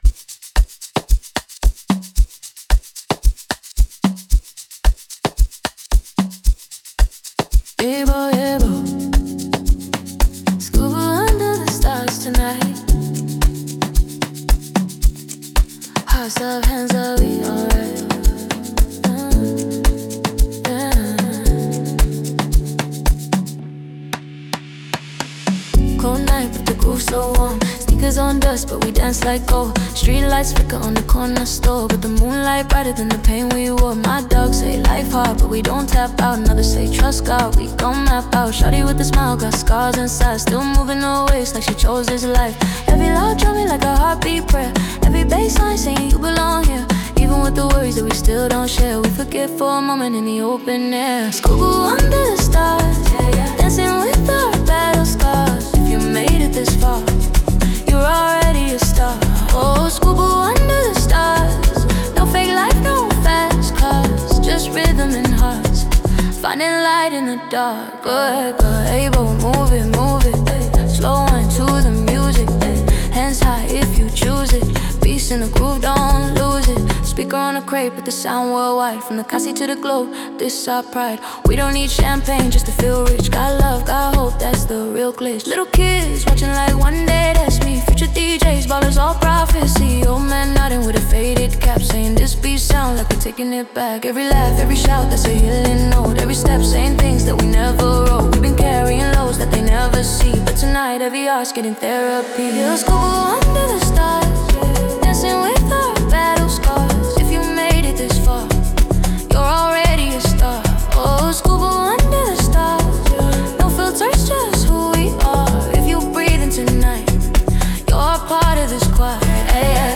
Amapiano 2025 Non-Explicit